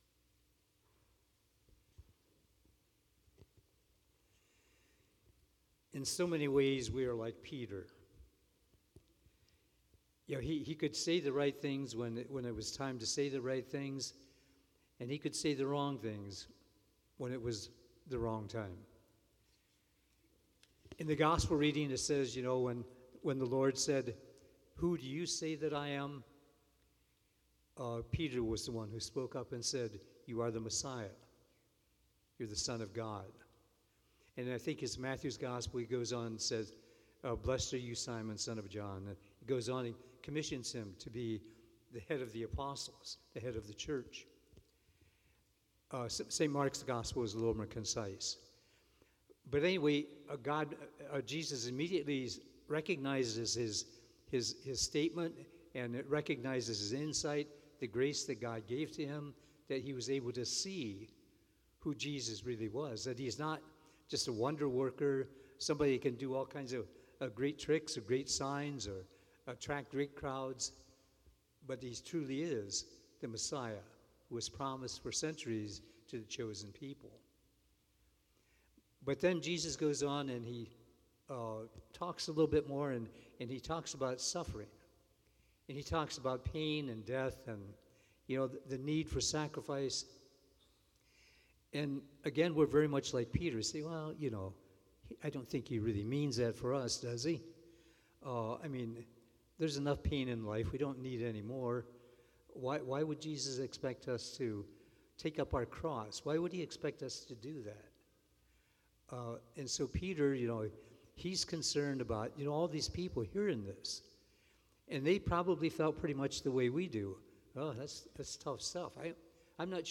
There is some distortion 3 minutes in, but you can still understand what he is saying.